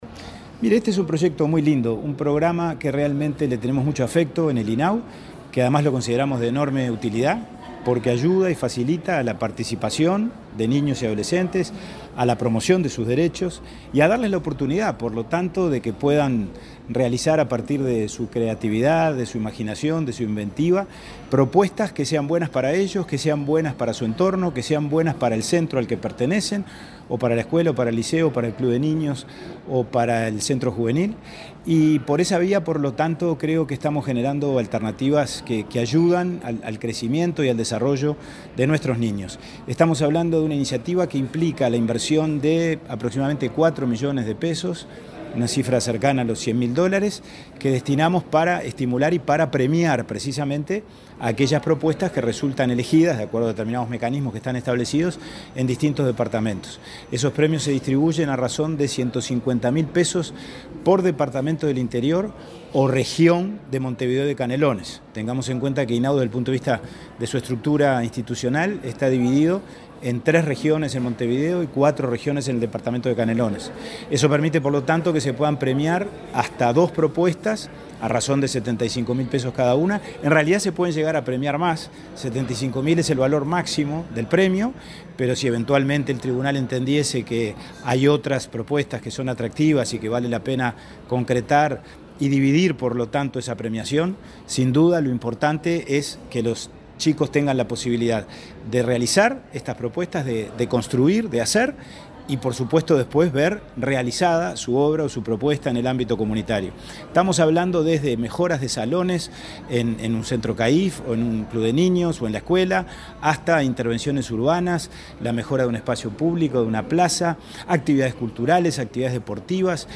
Declaraciones del presidente de INAU a la prensa
El presidente del INAU, Pablo Abdala, dialogó con la prensa luego de presentar en Montevideo una nueva edición de los Fondos para Iniciativas de Niñas